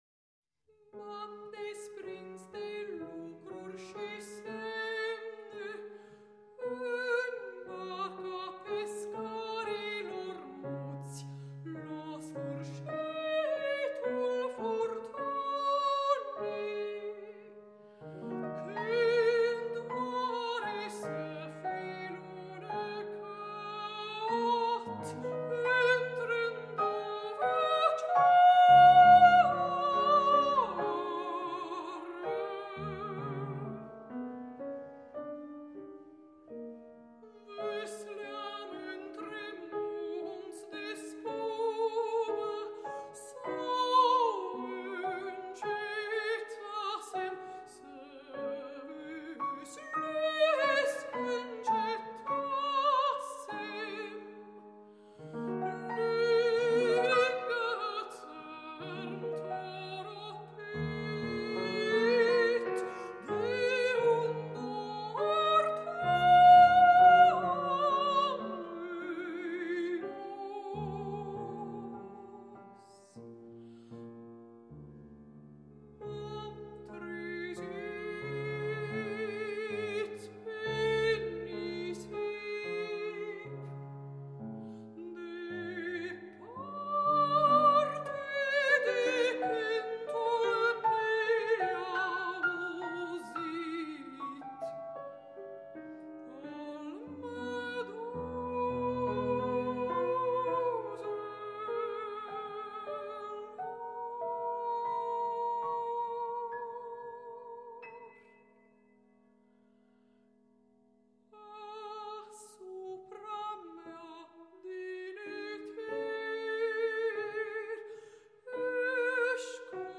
soprană
pian